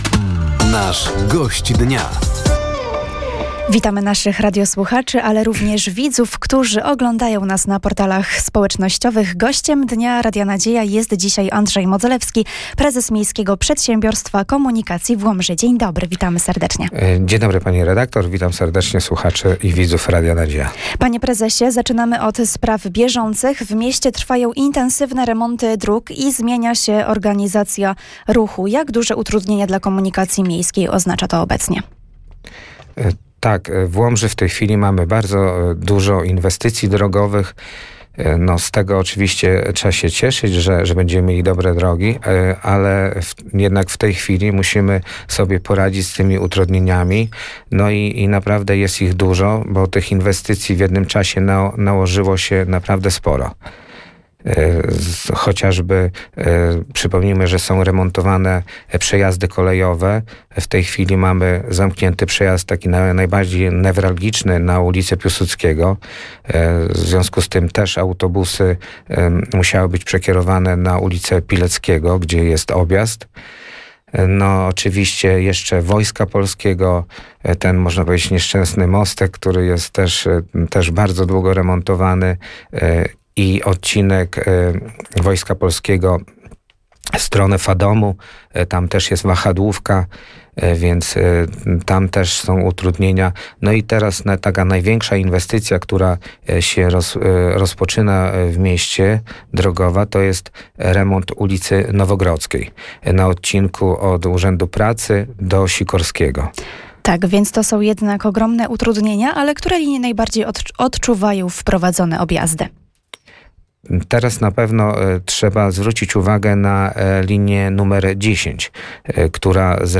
Zmiany w organizacji ruchu i kursowaniu autobusów w związku z trwającymi remontami dróg w mieście, zakup nowych autobusów elektrycznych, czy bezpieczeństwo w ruchu drogowym – to główne tematy rozmowy podczas audycji ,,Gość Dnia”.